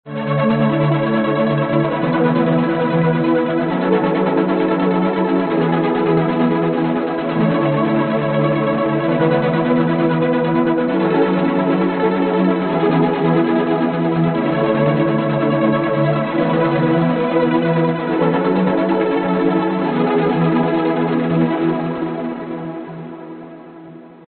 Tag: 美式英语 拍卖 计数 人声 声音